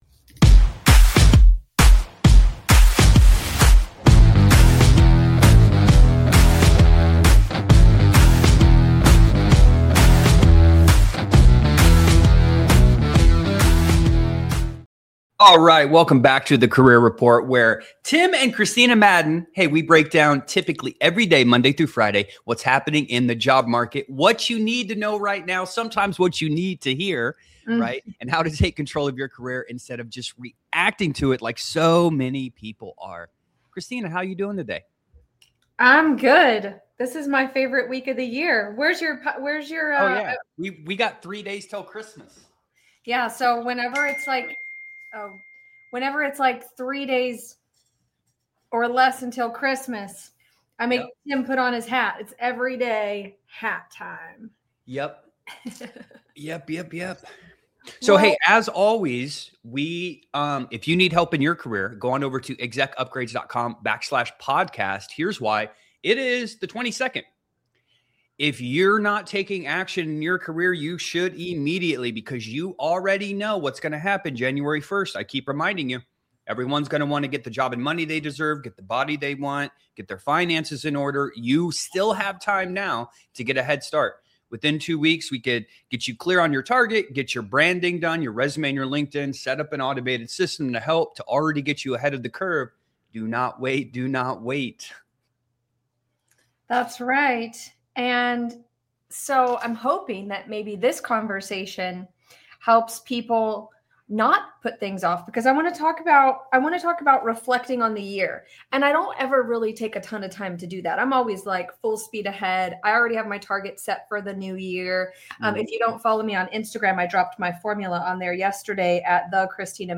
They walk through how to review the past year honestly, how to recognize wins that are easy to overlook, and how to carry lessons forward without beating yourself up over what didn’t go as planned. This conversation is about clarity, momentum, and setting yourself up for a stronger year ahead, not just creating another list of goals.